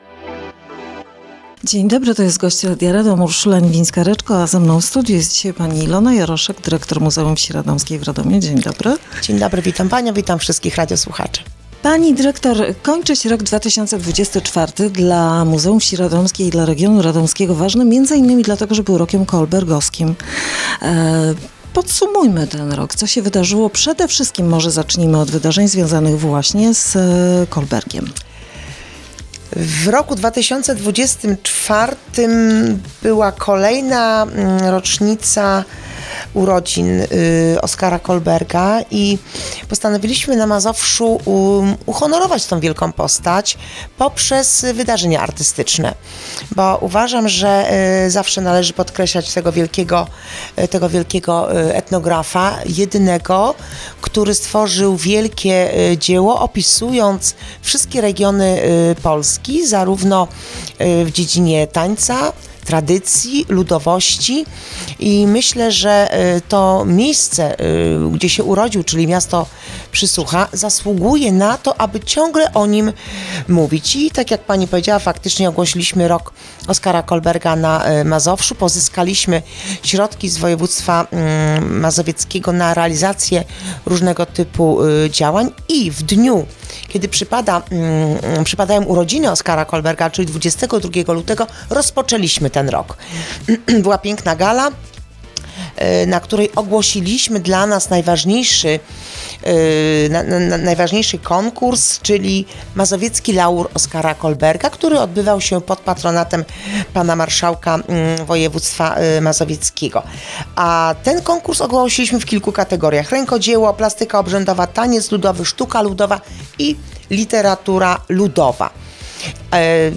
Gość Radia Radom